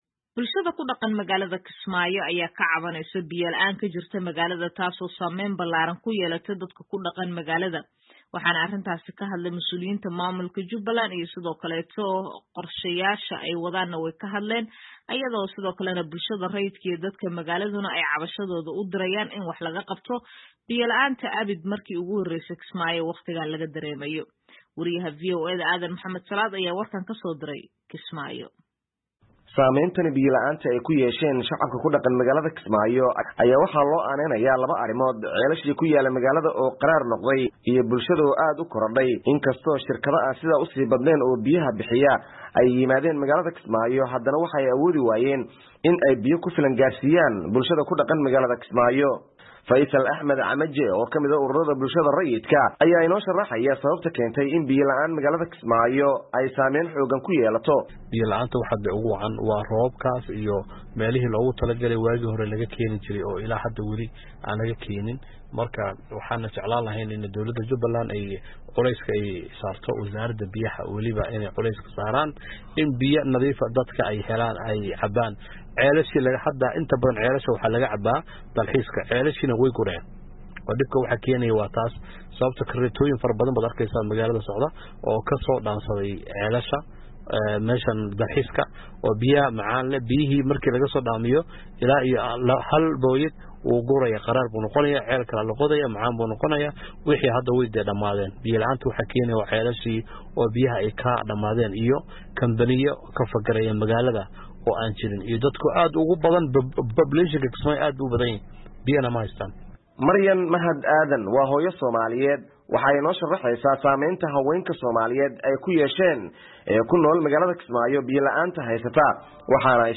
ayaa warkan kasoo direy magaalada Kismaayo.